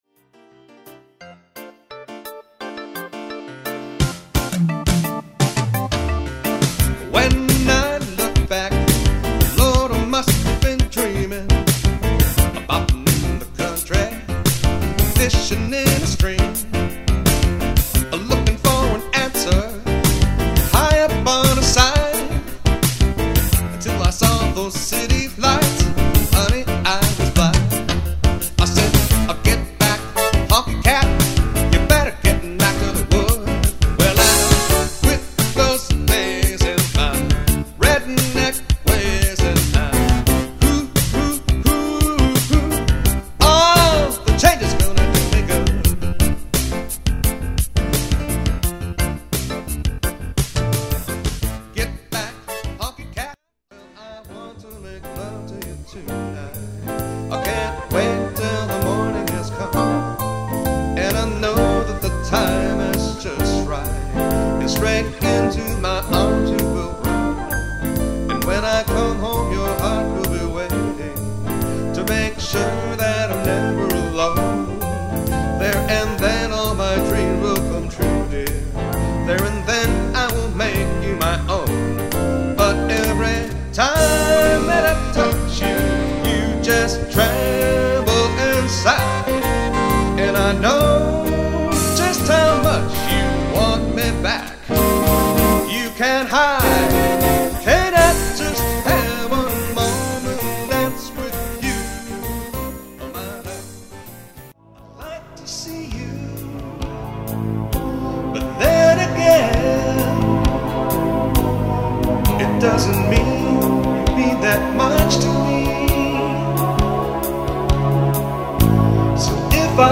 guitarist
Dallas Guitarist & Singer